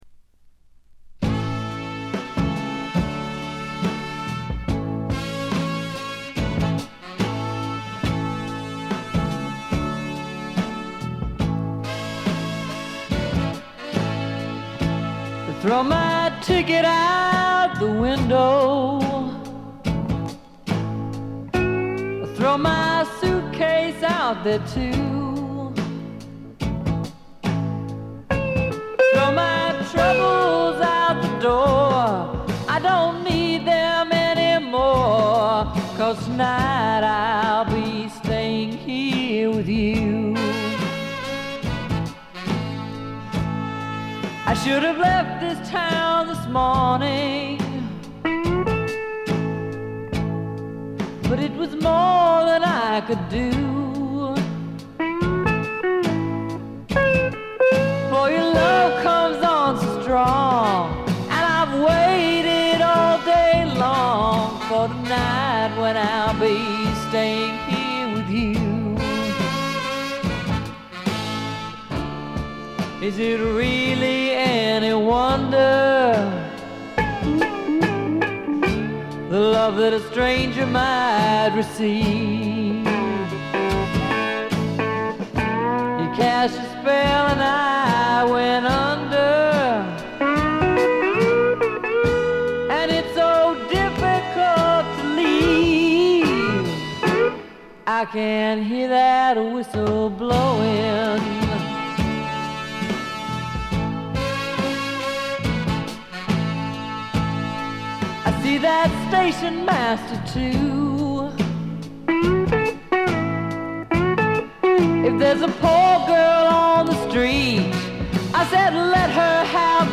ほとんどノイズ感無し。
試聴曲は現品からの取り込み音源です。
Recorded At - Muscle Shoals Sound Studios